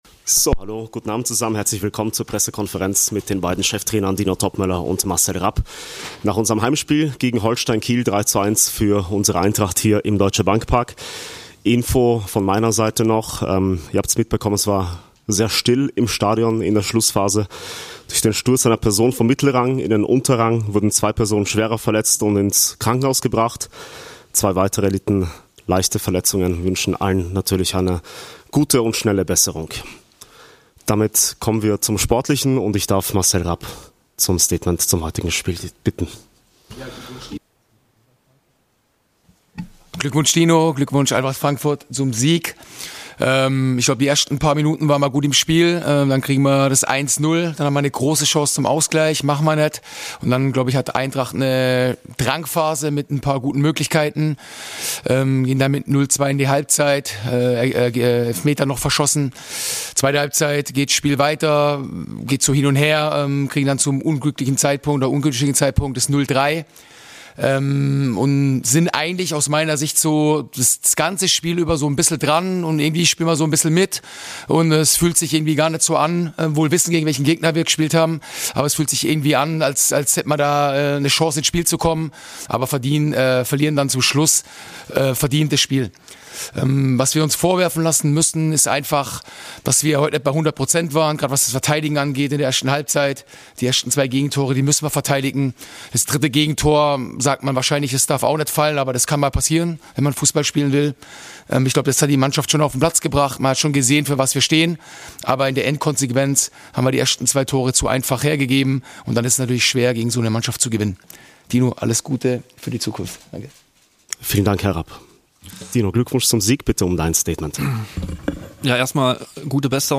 Die Pressekonferenz mit den beiden Cheftrainer Dino Toppmöller und Marcel Rapp nach dem Bundesliga-Heimspiel gegen Aufsteiger Holstein Kiel.